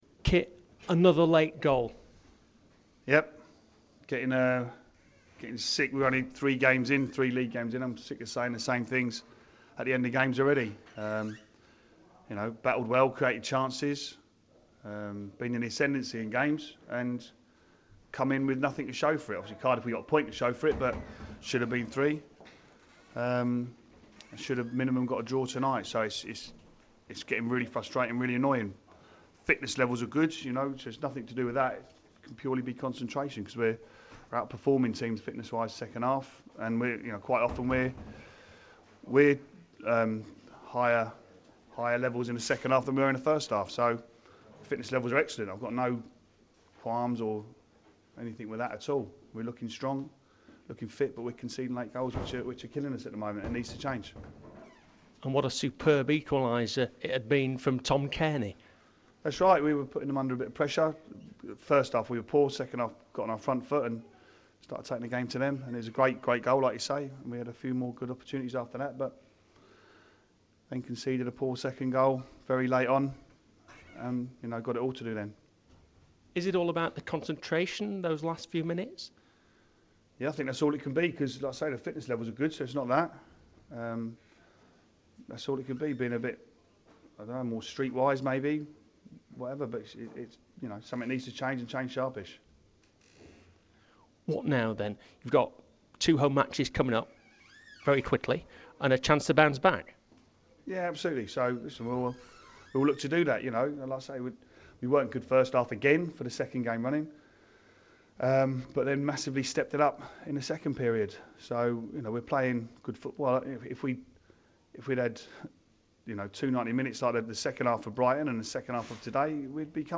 LISTEN: Fulham manager Kit Symons speaks to BBC London after seeing his side lose 2-1 at Hull City.